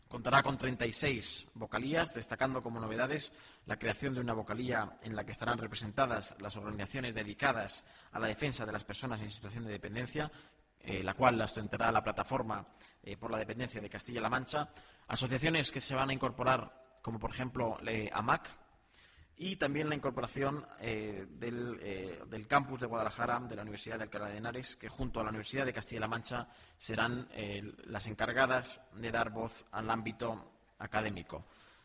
portavoz_gobierno_-_consejo_mujer.mp3